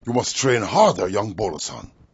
zen_umusttrainharder.wav